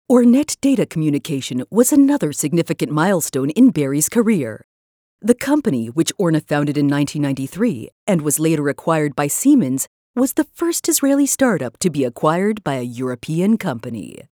Spécialisée dans l'e-learning et la narration technique, elle offre un doublage professionnel, chaleureux, autoritaire et rapide, idéal pour les projets d'entreprise, éducatifs et axés sur les personnages.
Documentaires
* Studio traité acoustiquement, pour garantir un son propre et de haute qualité